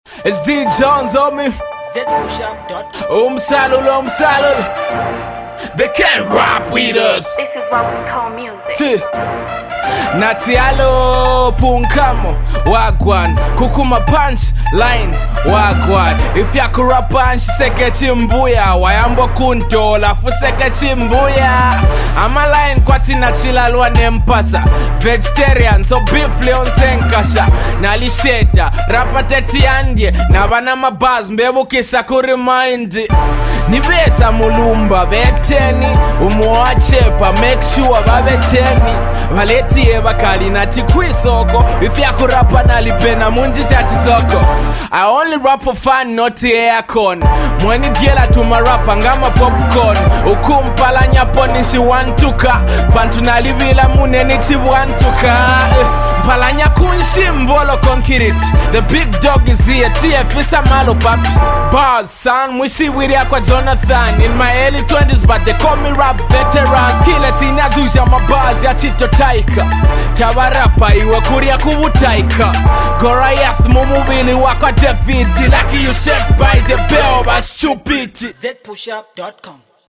MUSIC
rap sequence freestyle